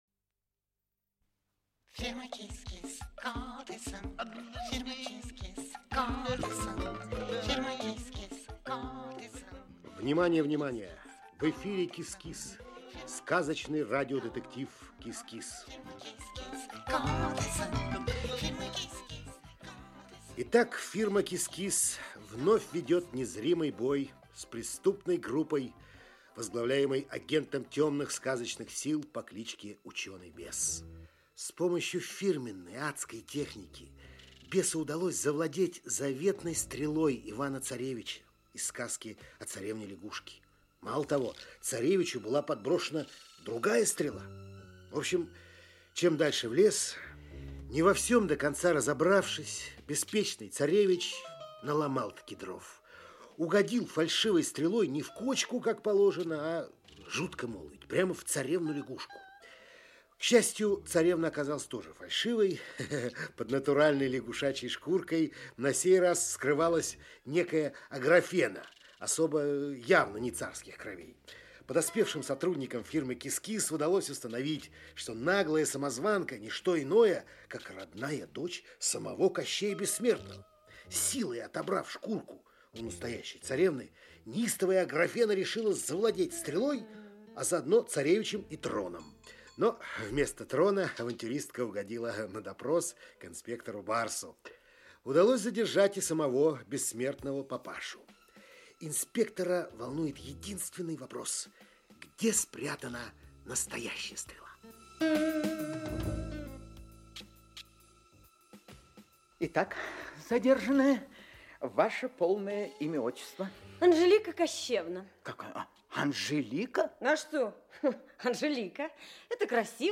Аудиокнига КИС-КИС. Дело № 5. "Отравленная стрела". Часть 3 | Библиотека аудиокниг